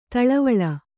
♪ taḷavaḷa